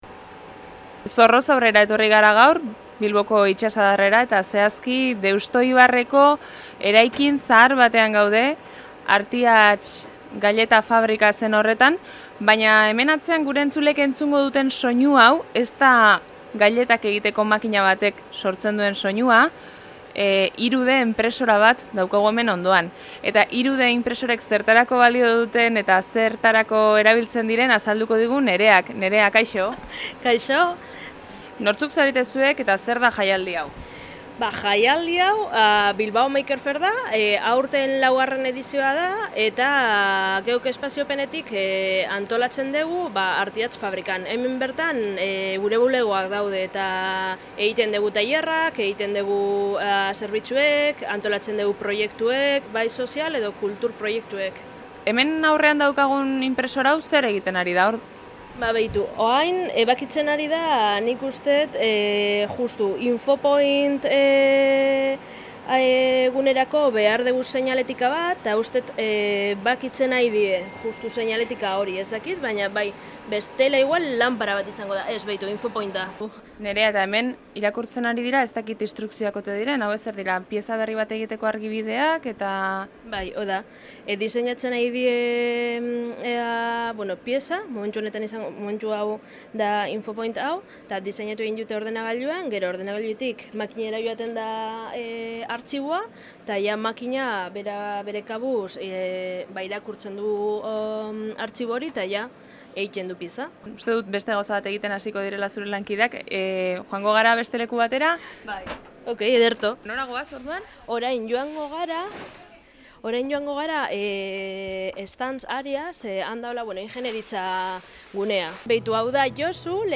Besteak beste: Leartiker, Domotek eta The Makeryko makerrekin egin dugu elkarrizketa.